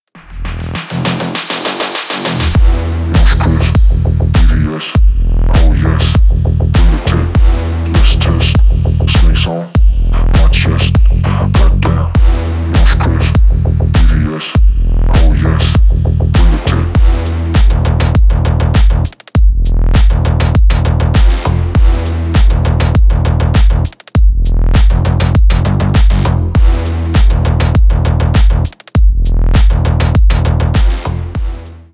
Поп Музыка
клубные # громкие